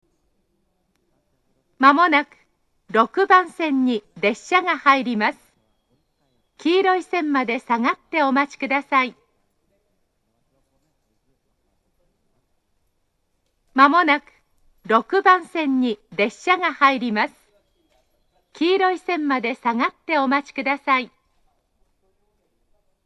６番線接近放送
５、６番線は良い収録場所がありません。
koriyama6bansen-sekkin.mp3